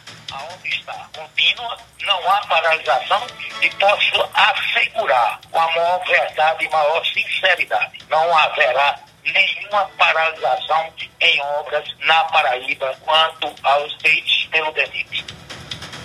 “A obra está continua, não há paralisação. E posso assegurar com a maior verdade, maior sinceridade, não haverá nenhuma paralisação em obras na Paraíba quanto aos feitos pelo DNIT”, afirmou durante entrevista ao programa Arapuan Verdade, da Rádio Arapuan FM.